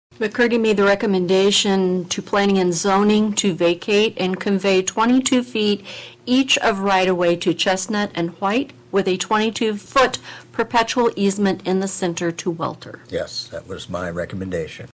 Following a lengthy discussion, City Councilman Pat McCurdy recommended the city split the ROW three ways. Atlantic City Clerk Barb Barrick read the following recommendation.